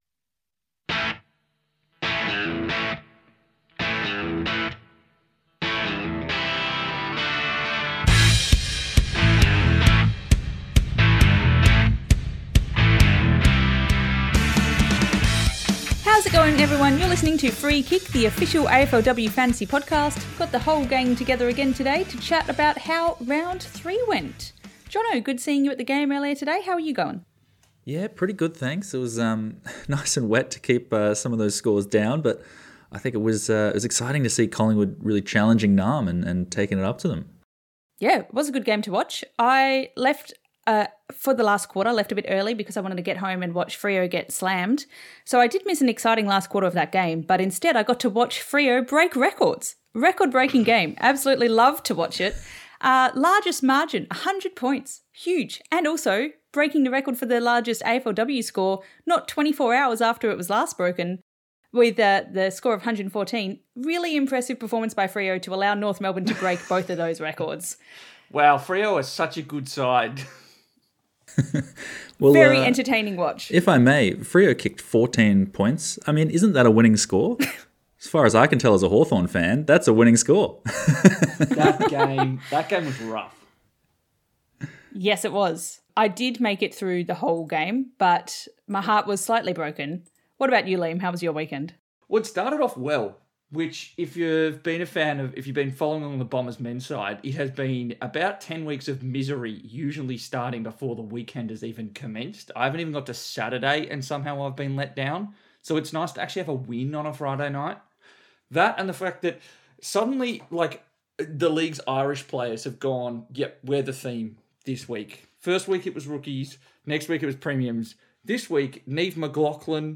The AFLW record for the highest score was broken TWICE (good, unless you're a Fremantle supporter) and amogst others, 2024 Grand Final Best on Ground Jas Garner went down injured (bad). Amongst this chaos the gang chat through their first Sweet 16 for the season